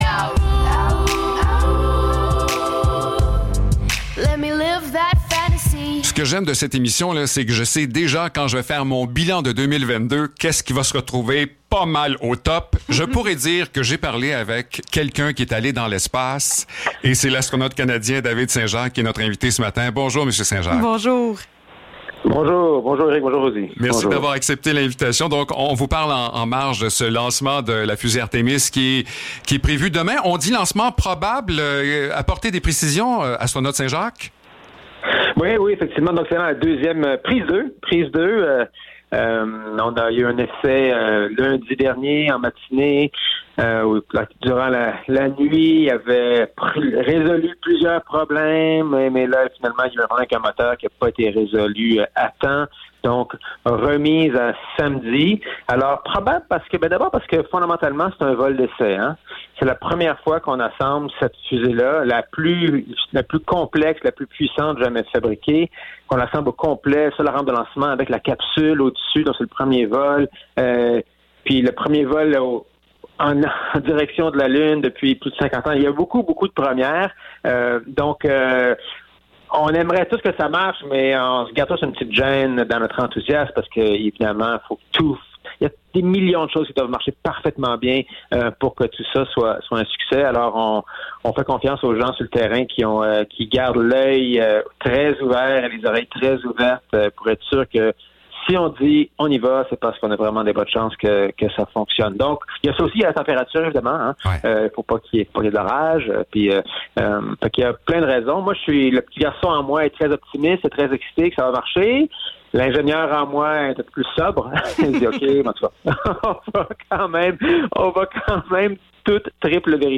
Entrevue avec l’astronaute David Saint-Jacques
Entrevue-David-St-Jacques.mp3